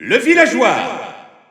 Announcer pronouncing male Villager in French PAL in victory screen.
Villager_French_EU_Alt_Announcer_SSBU.wav